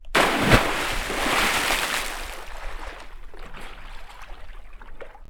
Water_10.wav